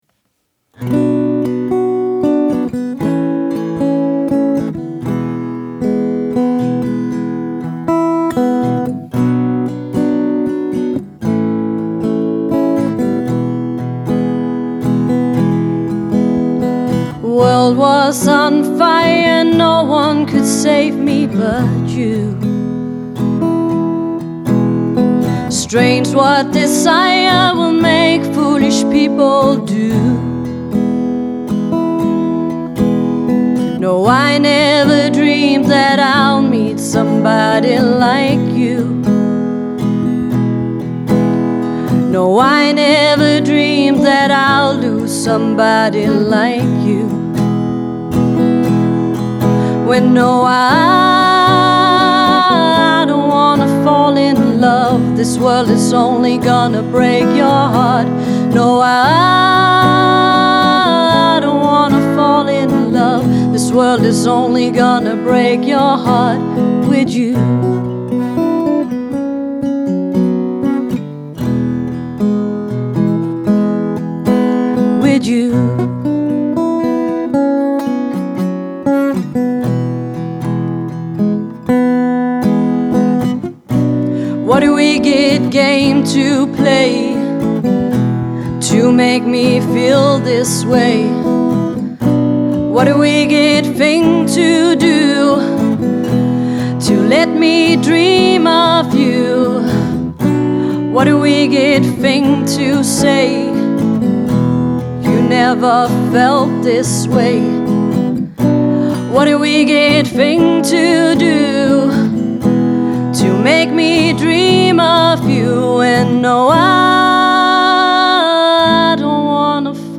Musikken er rolig og afslappet.